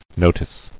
[no·tice]